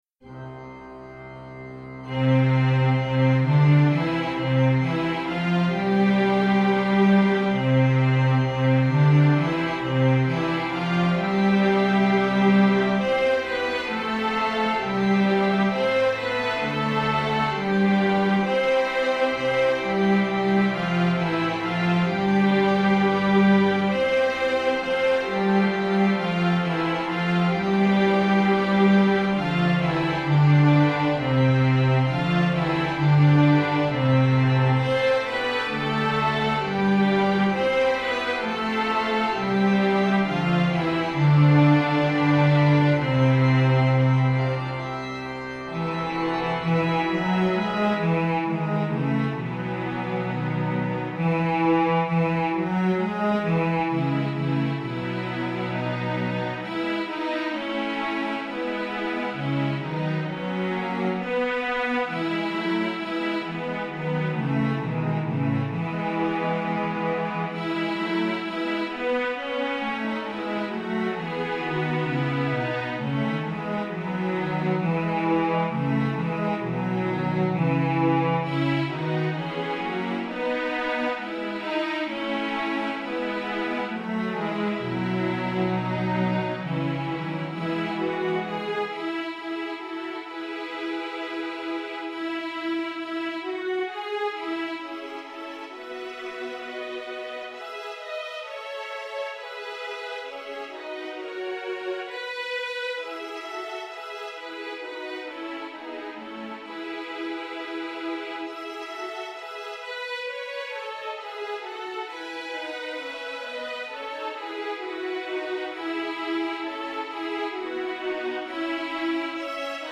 Text by St. Francis of Assisi, music by 17th Century German tune. SSAATTBB and organ.
Hear the music: MP3 file generated by computer MIDI file full score choir part You can also download this piece as a Finale 2006 file .